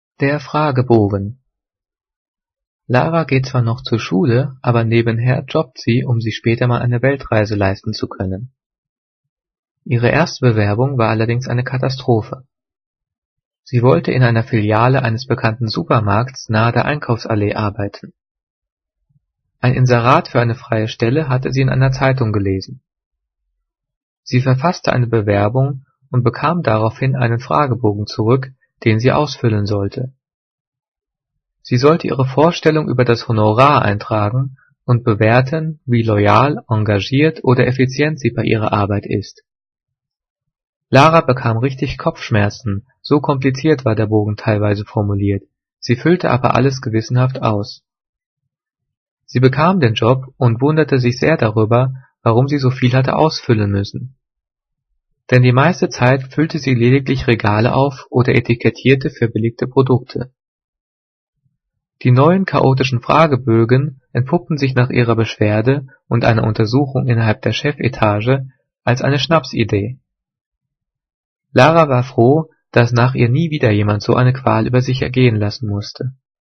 Gelesen:
gelesen-der-fragebogen.mp3